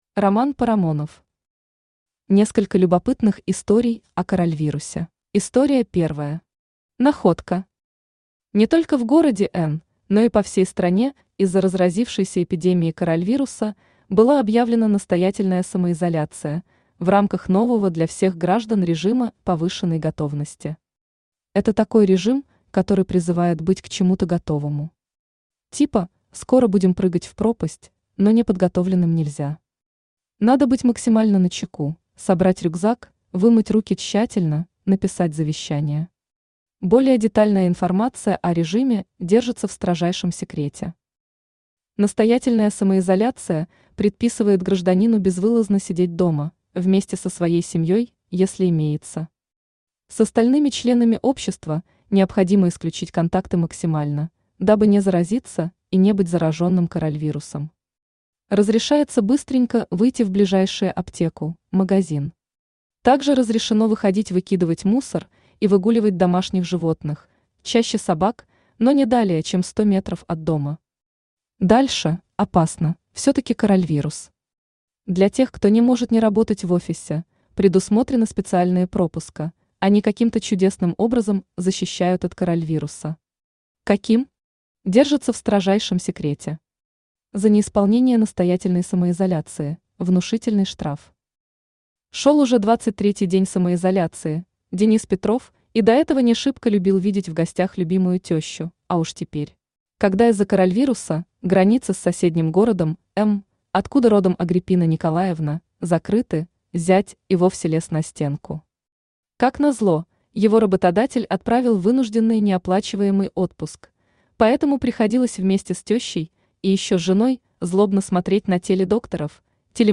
Аудиокнига Несколько любопытных историй о корольвирусе | Библиотека аудиокниг